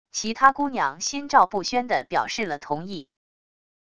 其他姑娘心照不宣的表示了同意wav音频生成系统WAV Audio Player